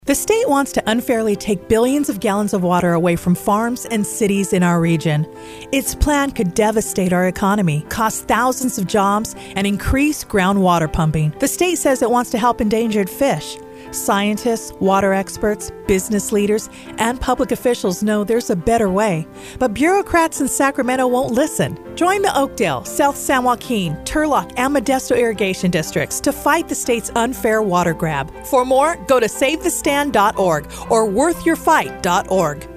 Audio: Listen to the joint Worth Your Fight / Save the Stan 30 second radio spot